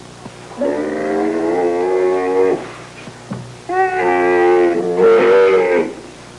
Camel Sound Effect
Download a high-quality camel sound effect.
camel.mp3